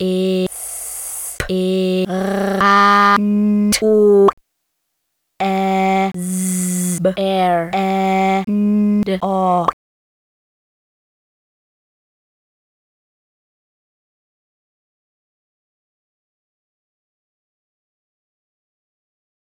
espeʁɑnto. ɛzbeəʳɛndʌ.